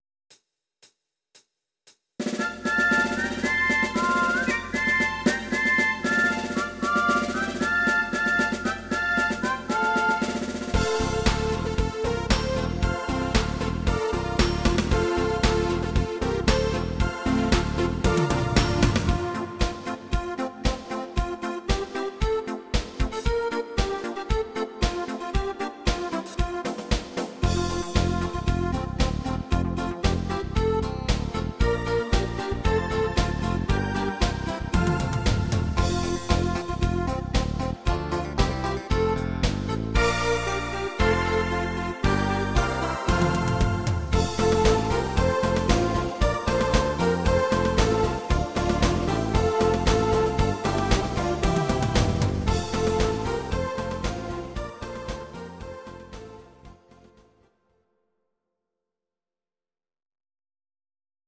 Audio Recordings based on Midi-files
German, Traditional/Folk, Volkst�mlich